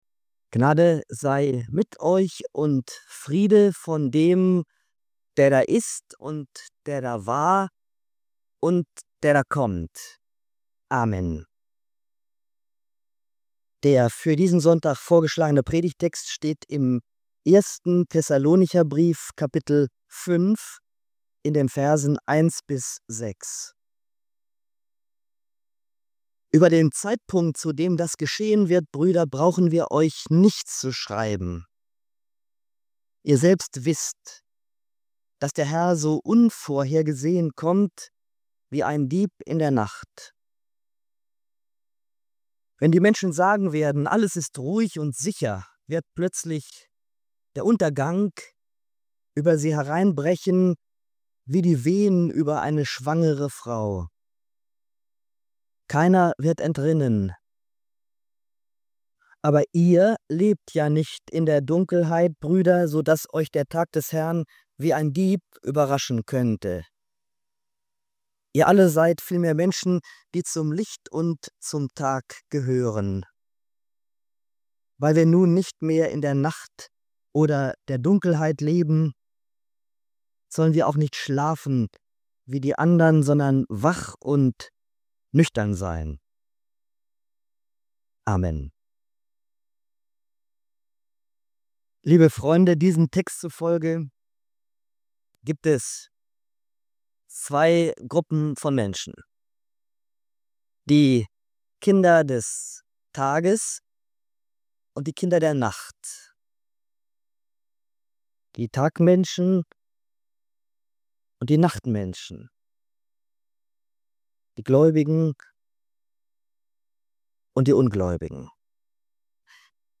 In dieser Predigt zu 1. Thessalonicher 5,1–6 geht es um eine klare Gegenüberstellung: Kinder der Nacht und Kinder des Tages.